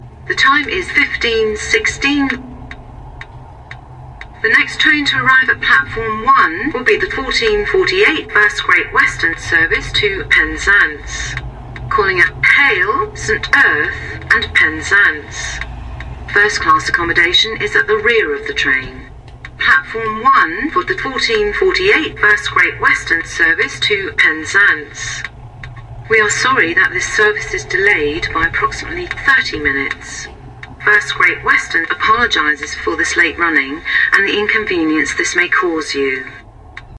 描述：只是一个简单的记录，一个HST以大约100MPH的速度从我身边呼啸而过，加速到125MPH然而
标签： 第一 HST 传球 铁路 速度 火车 西部